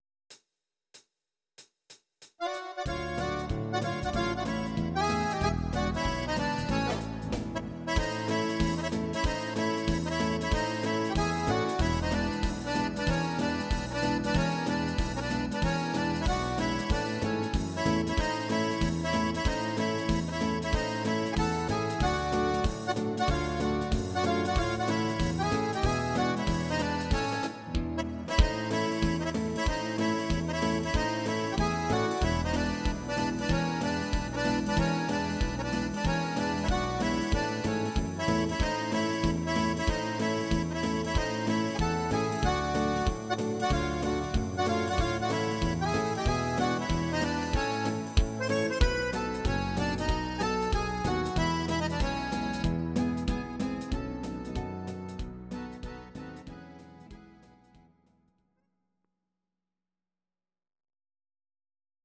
These are MP3 versions of our MIDI file catalogue.
Please note: no vocals and no karaoke included.
Your-Mix: Traditional/Folk (1154)
instr. Akkordeon